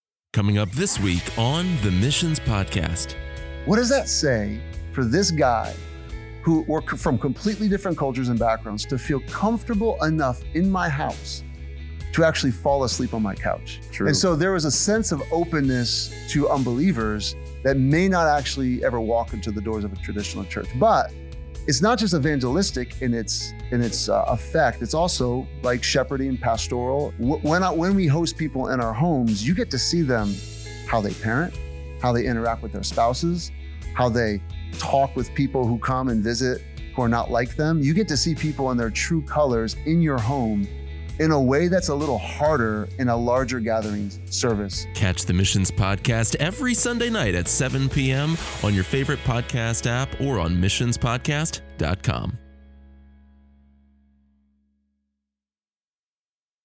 a local pastor and church planter